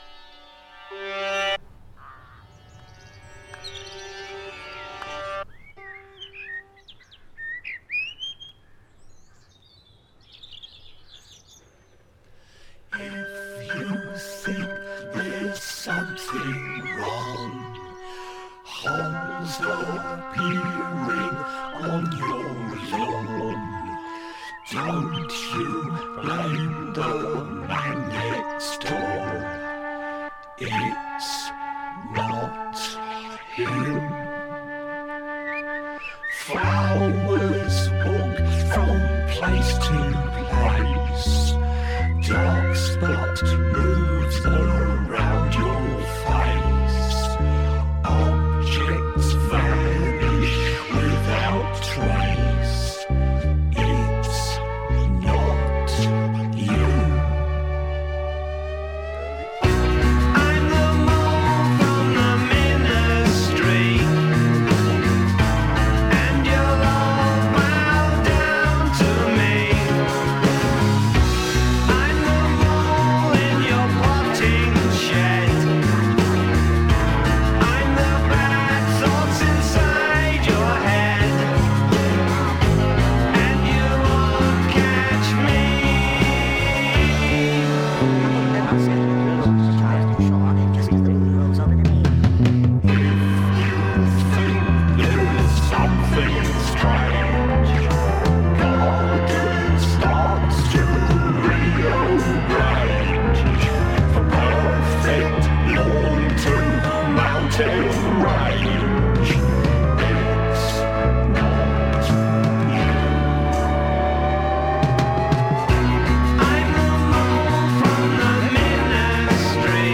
ひとことで言って完璧なサイケデリック・サウンドです。80年代に再構築された完璧なポップ・サイケ・ワールド。
試聴曲は現品からの取り込み音源です。
Mellotron, Piano, Organ, Guitar [Fuzz-tone Guitar]
Electric Bass
Drums [Drum Kit]